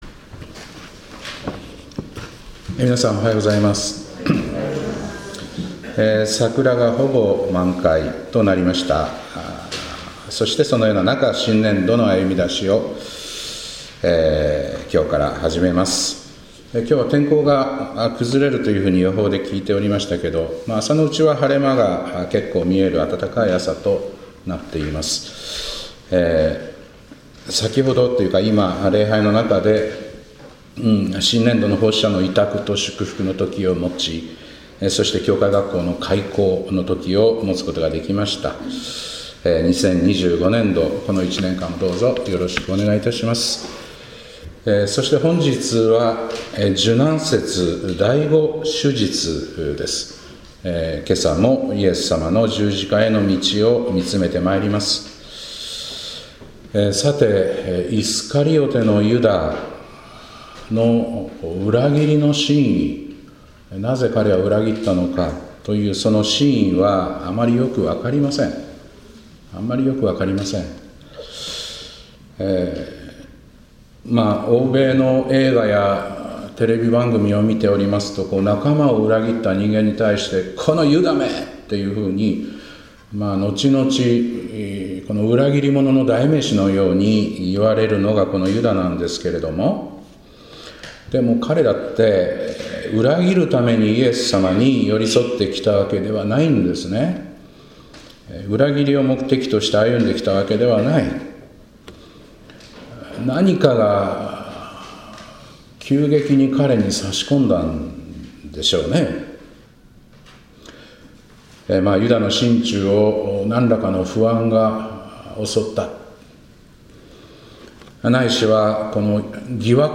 2025年4月6日礼拝「剣は鞘に、愛は抜身で」